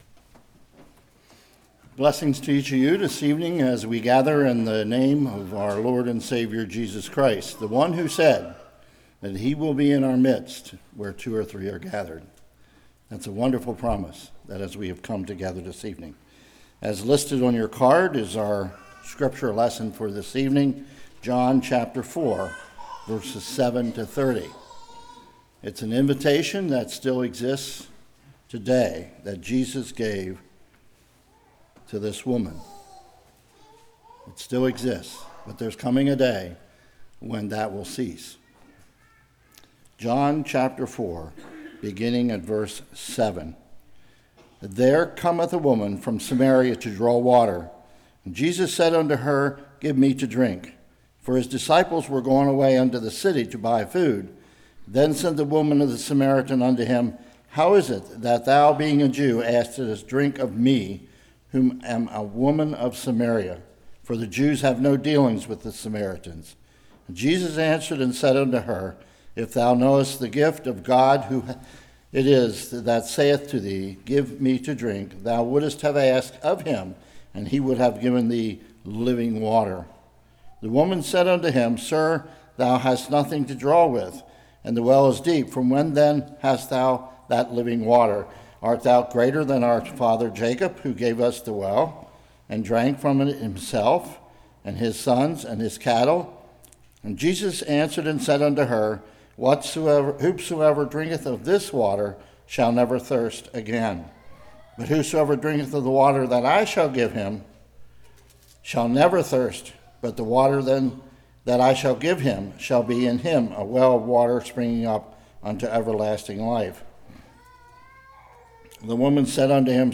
Service Type: Revival